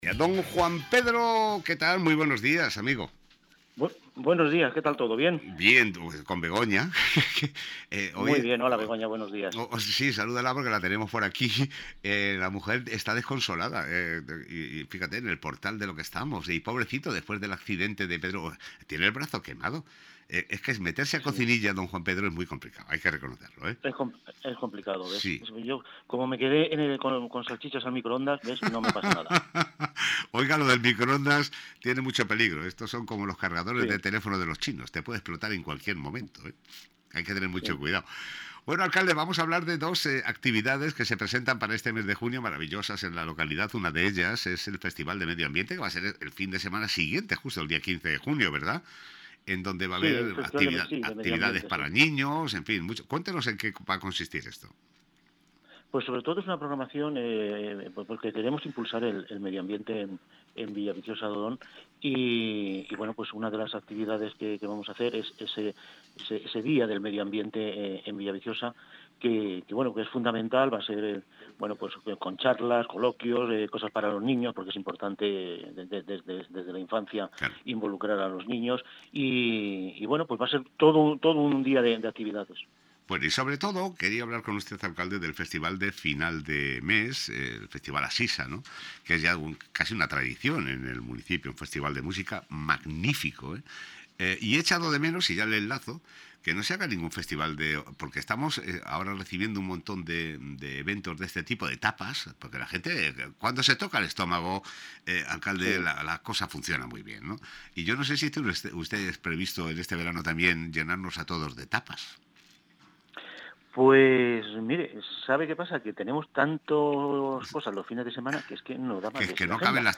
Entrevista a Juan Pedro Izquierdo, Alcalde de Villaviciosa de Odón